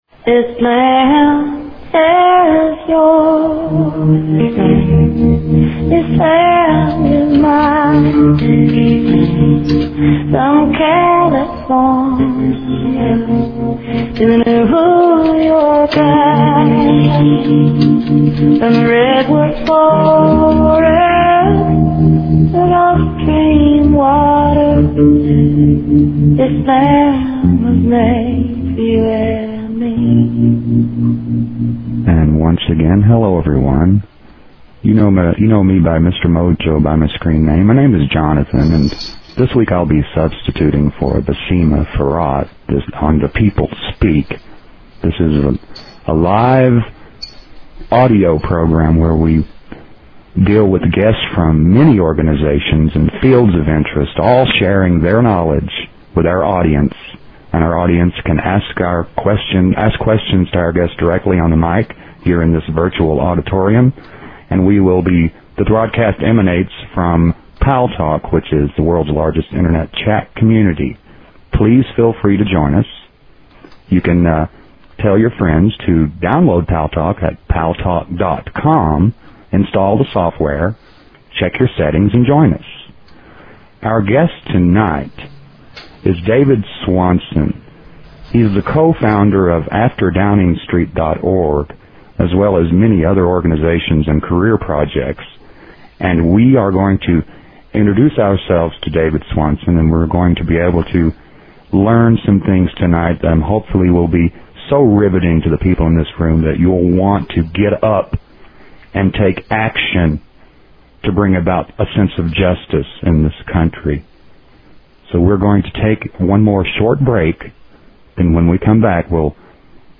The People Speak has evolved over the years with many great guests who have been interviewed by some very fine hosts.
The show features a guest interview from any number of realms of interest (entertainment, science, philosophy, healing, spirituality, activism, politics, literature, etc.).